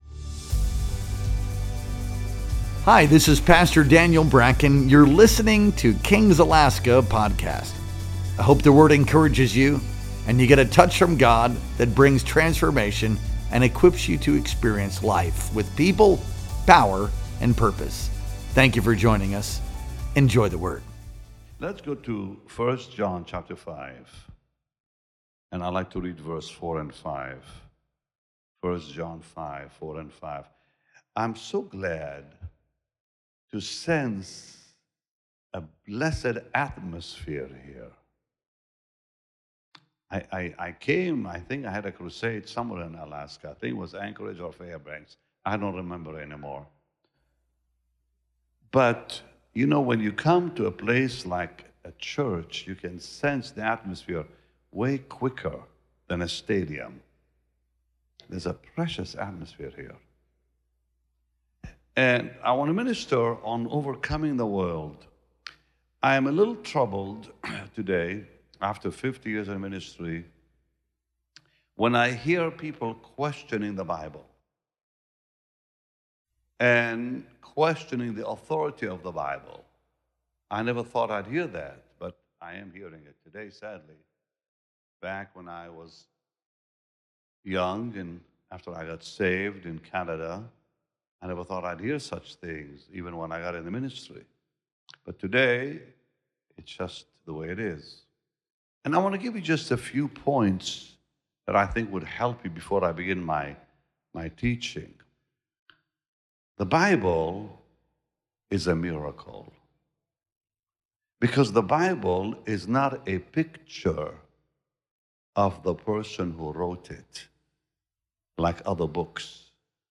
Our Sunday Night Worship Experience streamed live on May 18th, 2025.